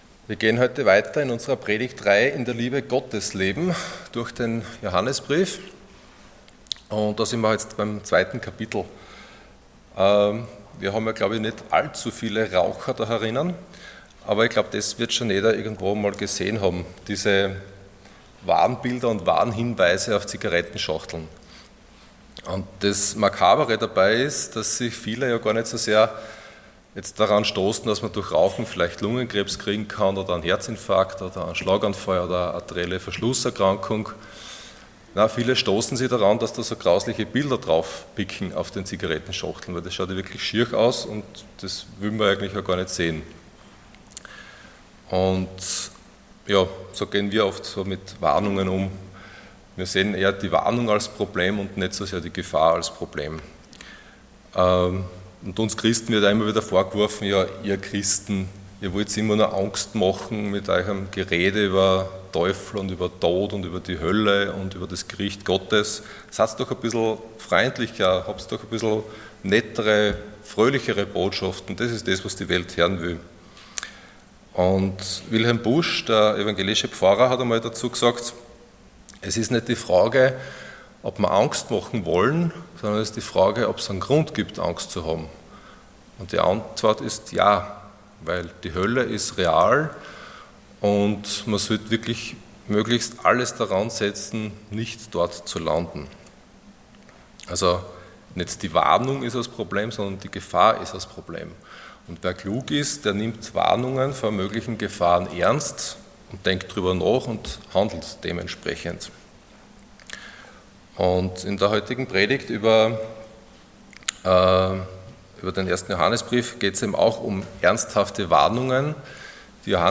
Passage: 1 John 2:18-27 Dienstart: Sonntag Morgen